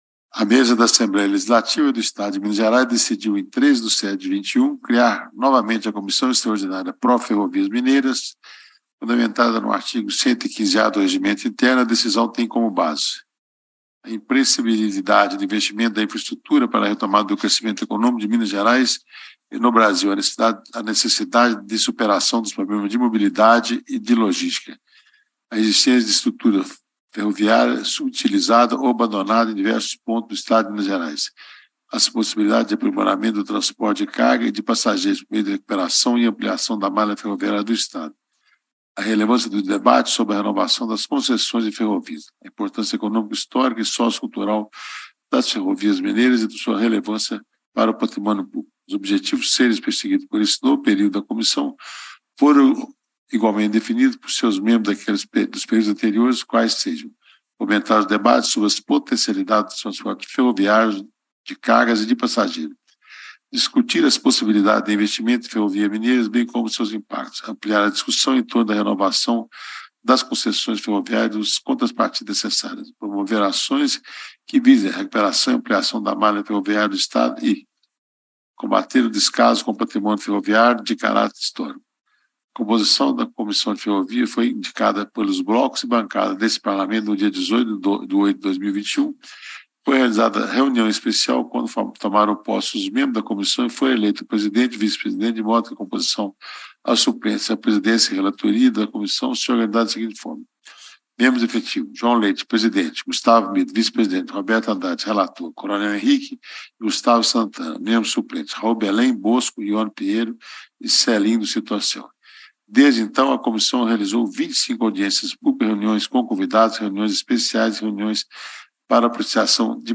O documento foi lido pelo Deputado Roberto Andrade (PSD), relator dos trabalhos, que faz observações sobre o setor ferroviário e destaca ações que devem ser implementadas.
Discursos e Palestras